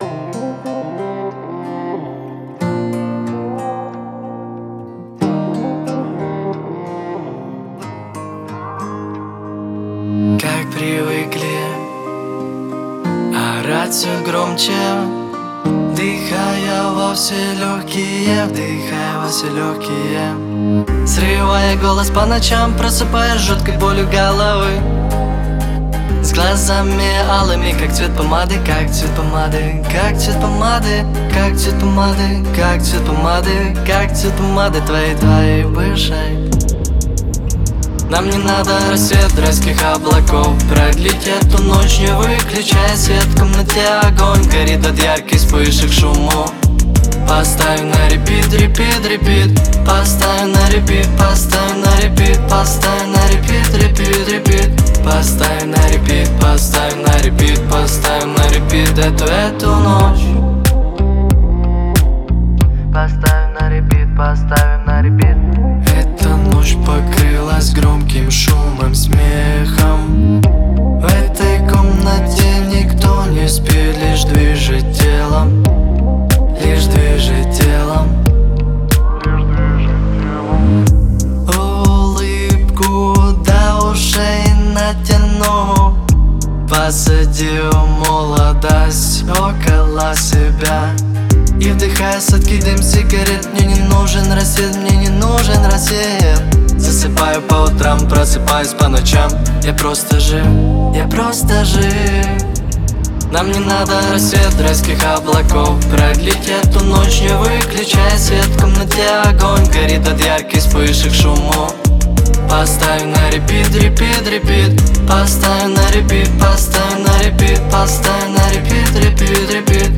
энергичная песня в жанре поп-рэп